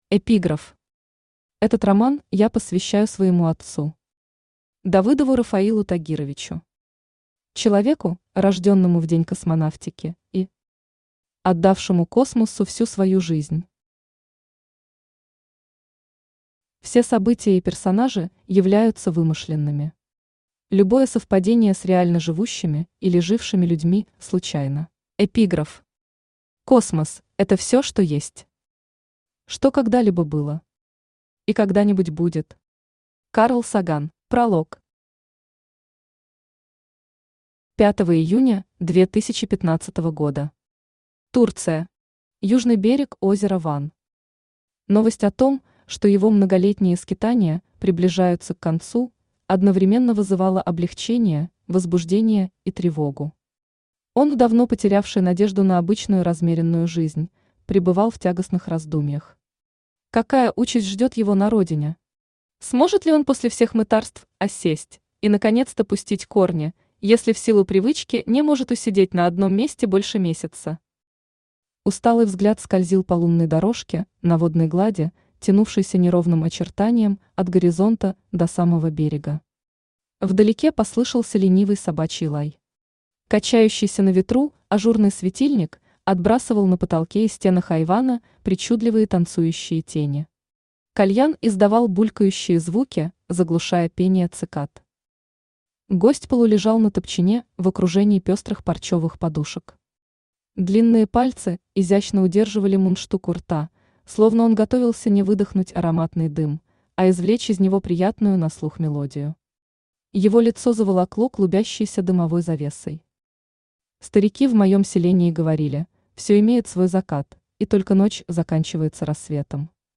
Аудиокнига Падение Вавилона | Библиотека аудиокниг
Aудиокнига Падение Вавилона Автор Инесса Давыдова Читает аудиокнигу Авточтец ЛитРес.